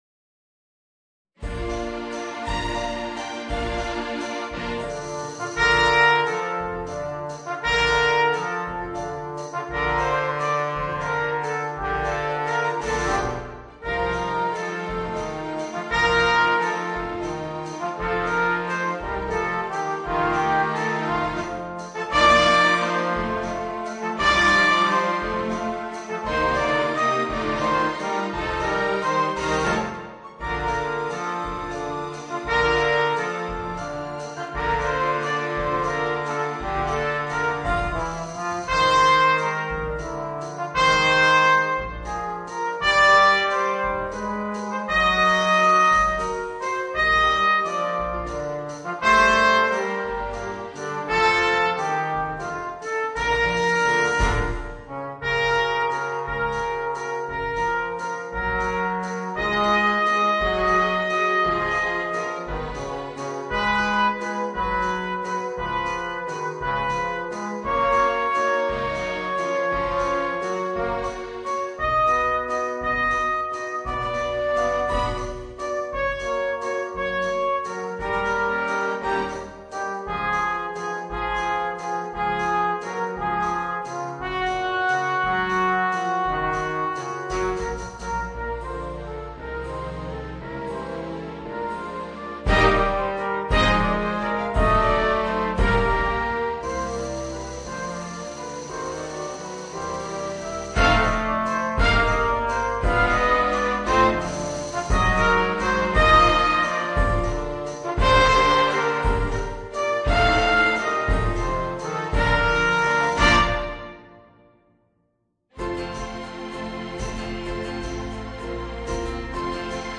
Trumpet, Trombone and Rhythm Section and Strings